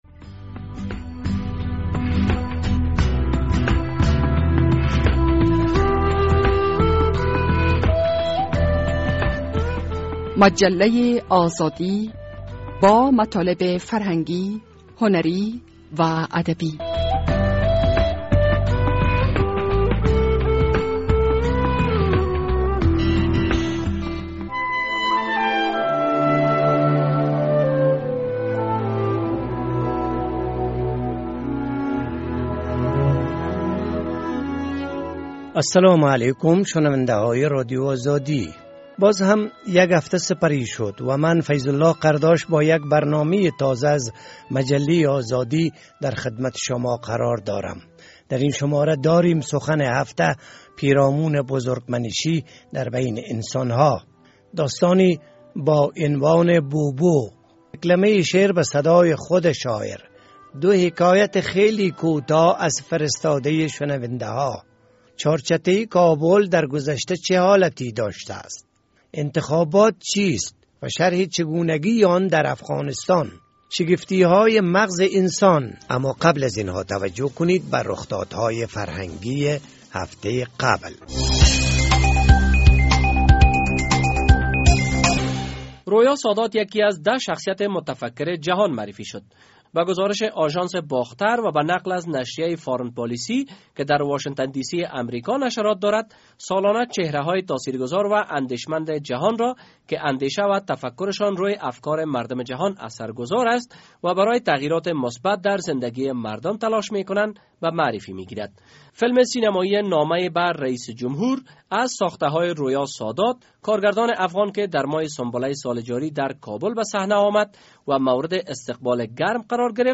در این شماره از مجله آزادی داریم، سخن هفته پیرامون بزرگمنشی در میان انسان‌ها، داستانی با عنوان (بوبو)، دکلمه شعر به ...